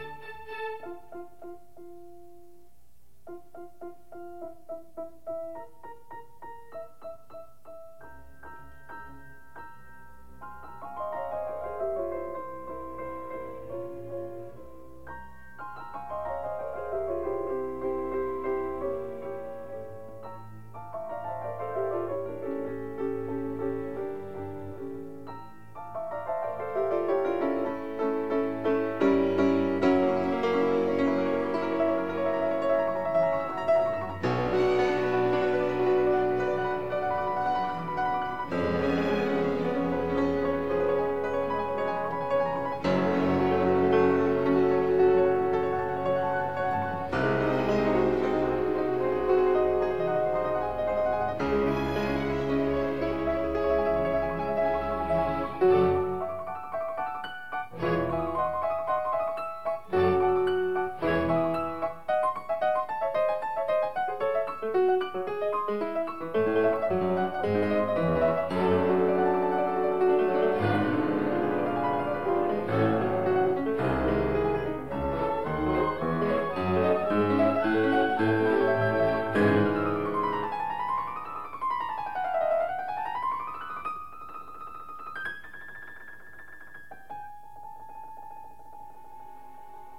Soloist
Recorded October 4, 1962 in the Ed Landreth Hall, Texas Christian University, Fort Worth, Texas
Concertos (Piano)
performed music